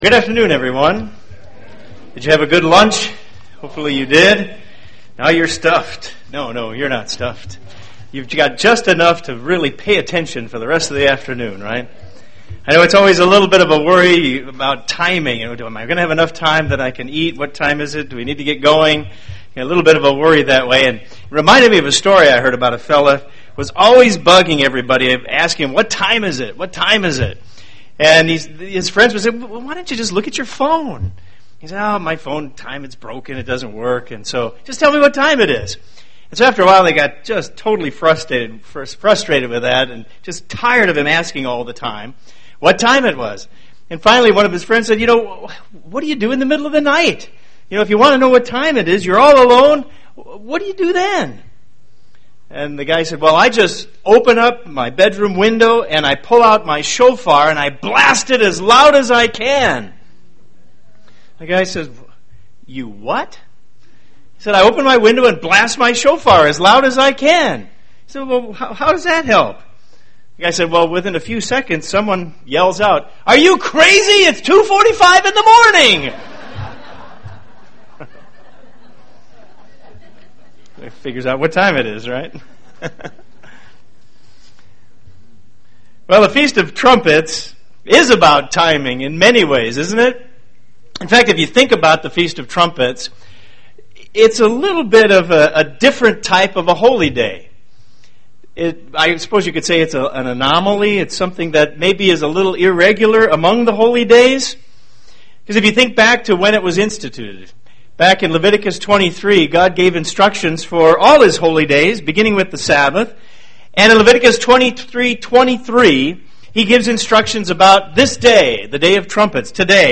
God has made Himself known by sound at times in the past, and a loud sound will happen again when Jesus returns. This message was given on the Feast of Trumpets.